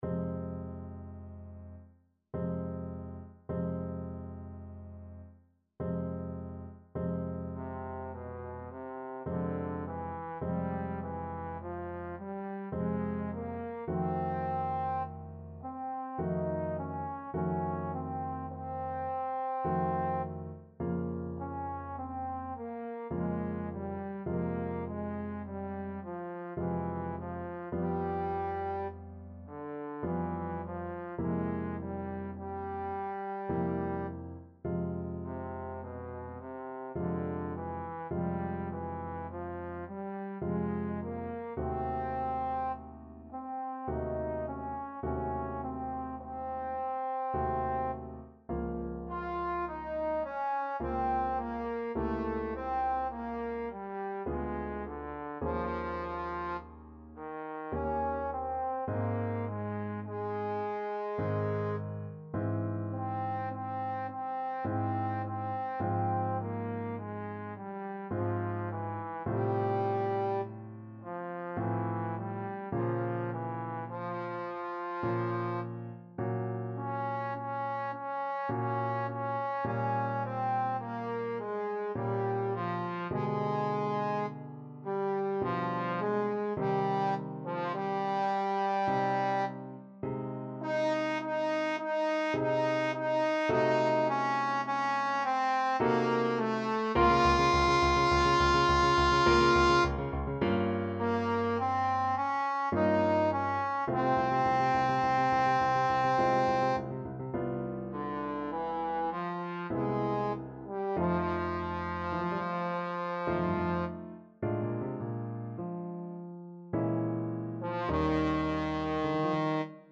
Classical
Trombone version